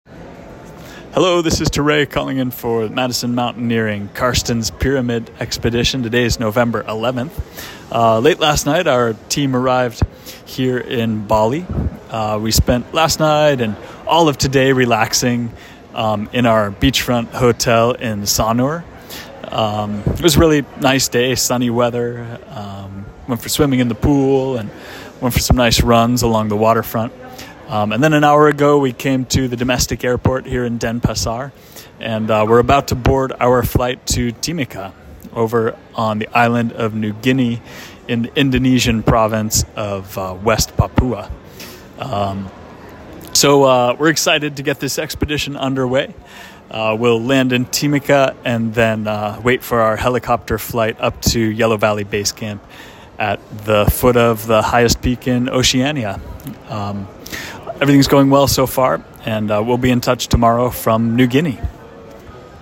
Our team flew by helicopter and arrived safely at Yellow Valley base camp today.